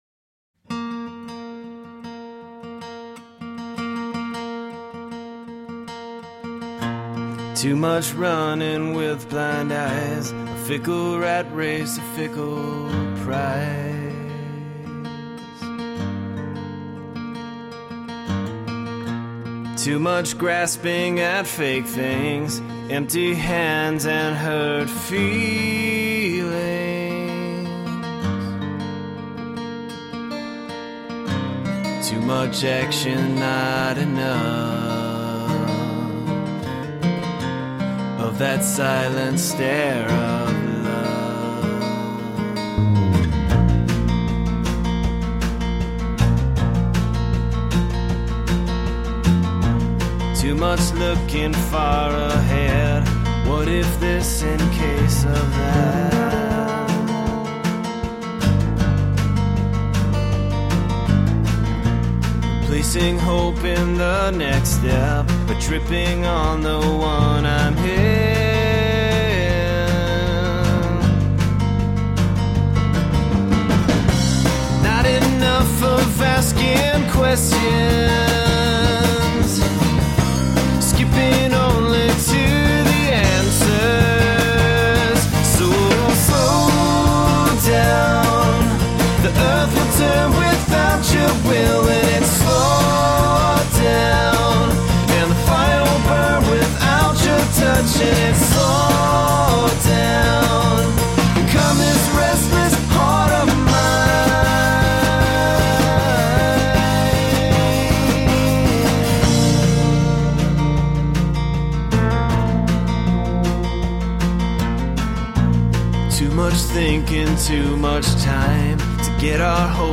Tagged as: Alt Rock, Folk-Rock